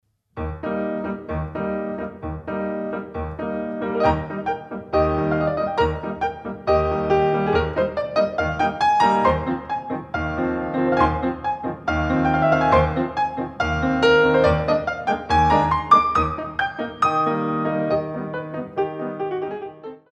Grand Allegro 2